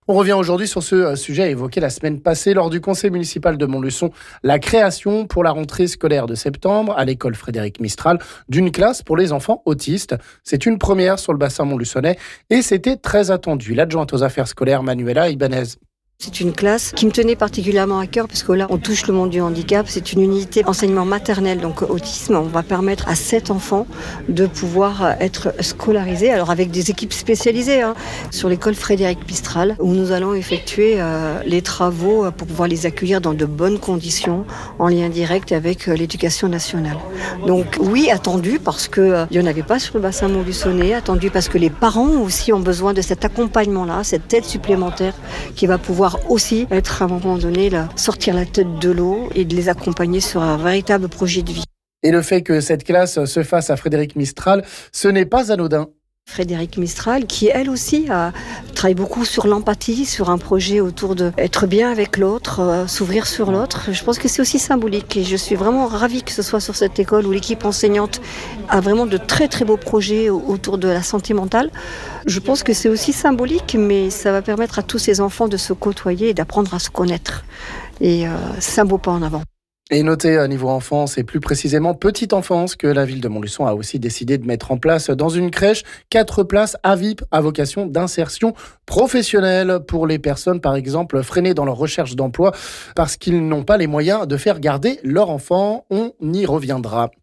L'adjointe aux affaires scolaires Manuela Ibanez nous en dit plus...